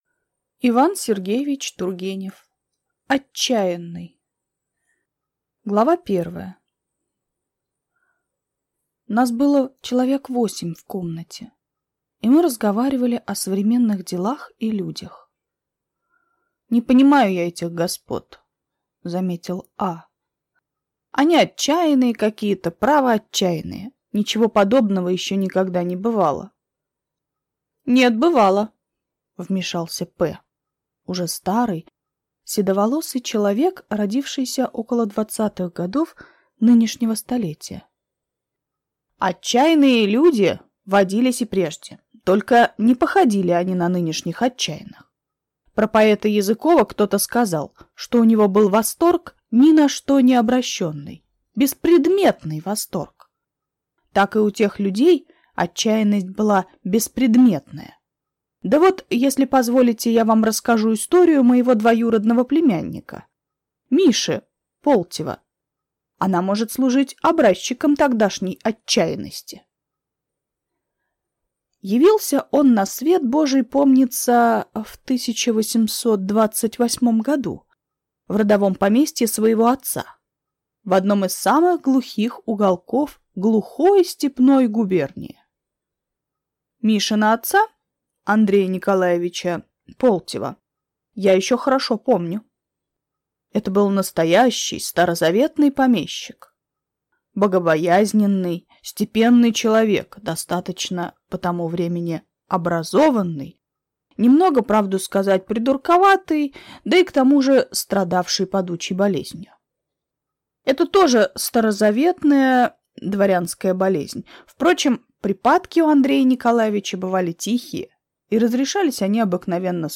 Аудиокнига Отчаянный | Библиотека аудиокниг